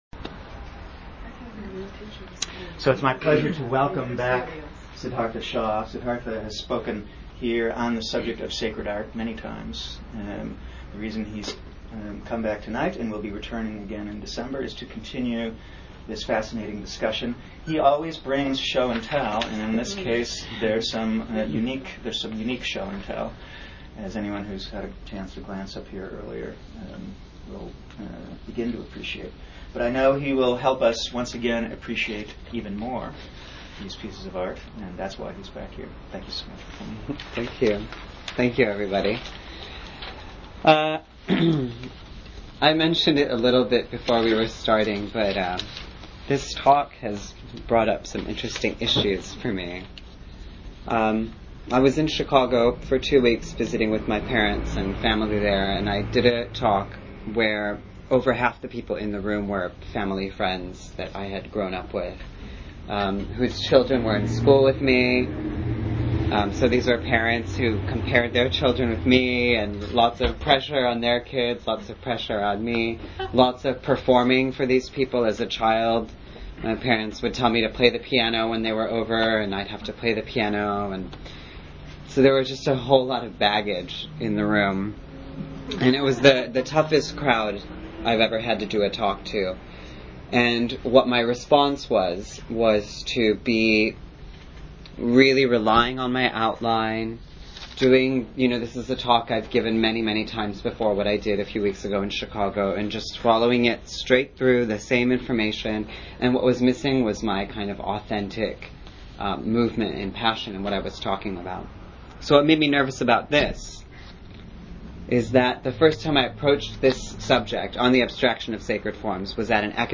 Archive of an event at Sonoma County's largest spiritual bookstore and premium loose leaf tea shop.